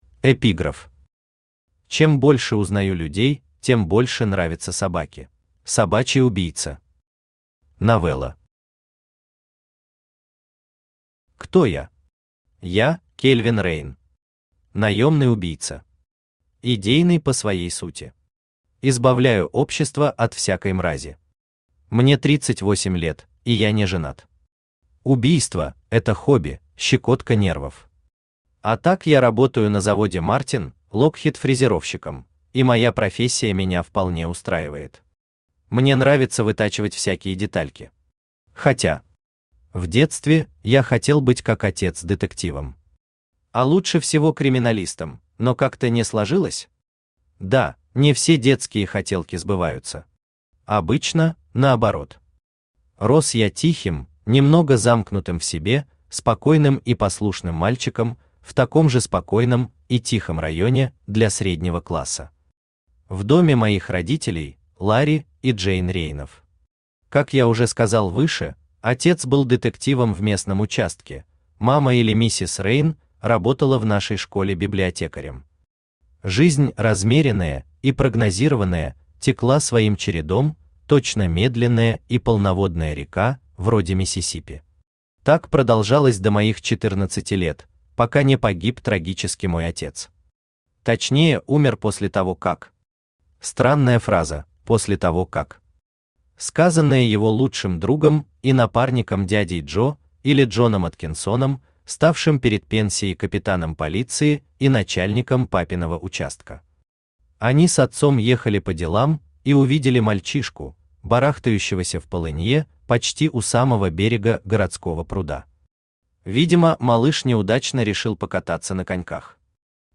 Аудиокнига Розовый слон | Библиотека аудиокниг
Aудиокнига Розовый слон Автор Константин Николаевич Кудрев Читает аудиокнигу Авточтец ЛитРес.